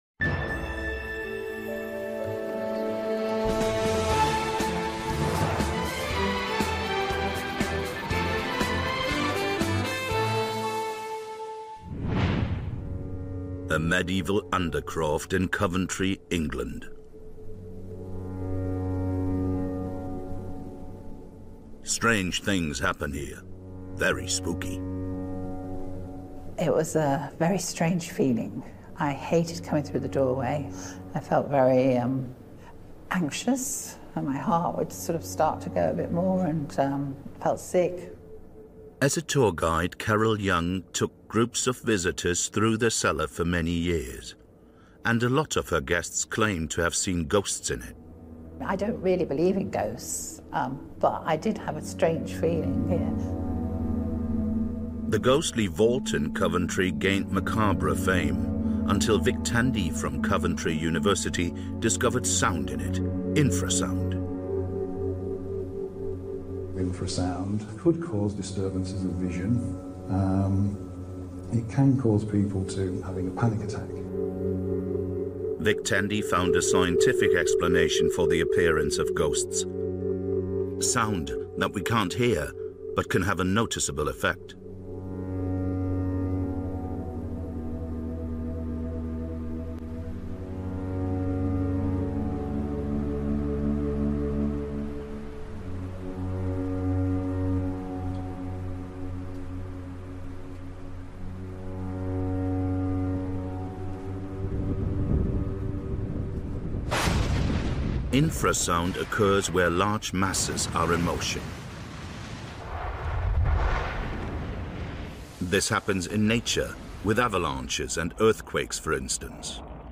Infrasound caused by Industrial Wind Turbines, and upon this basis an Important 28-minute documentary by the science program “planet e.” of the second German television ZDF — November 4, 2018